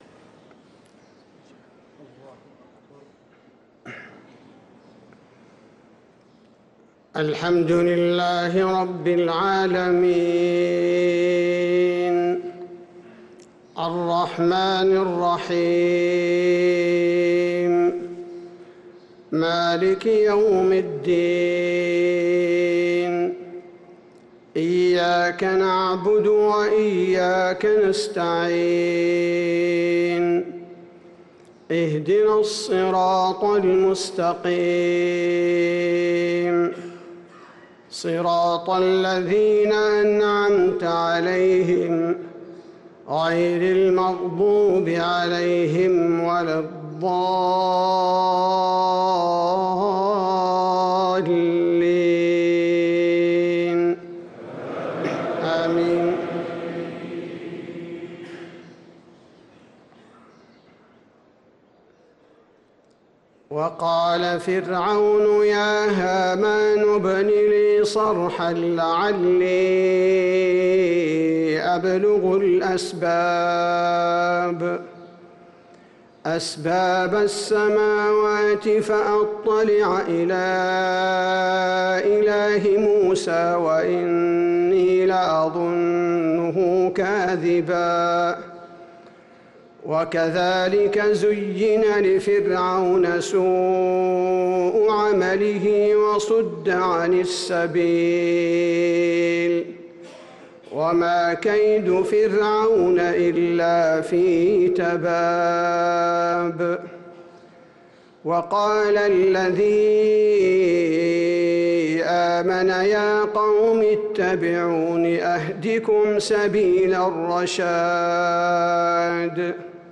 صلاة الفجر للقارئ عبدالباري الثبيتي 9 رمضان 1445 هـ
تِلَاوَات الْحَرَمَيْن .